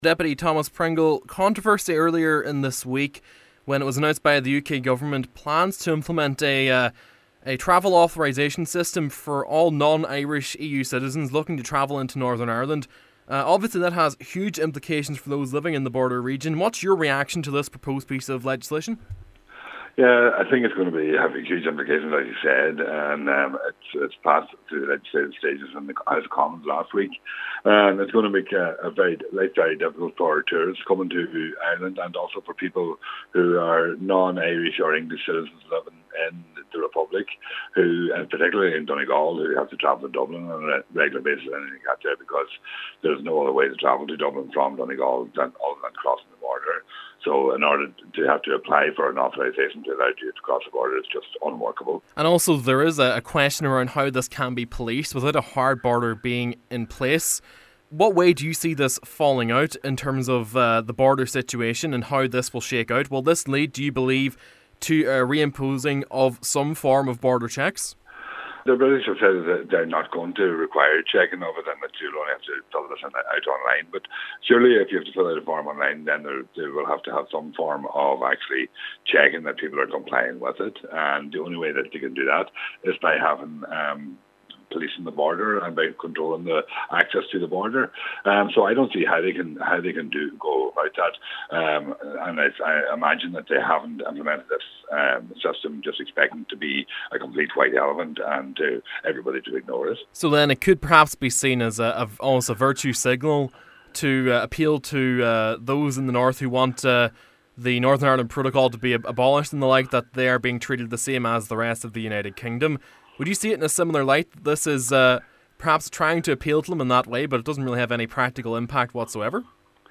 Deputy Pringle says that despite assurances from the British Government – he doesn’t see how their proposed system would work without checks……….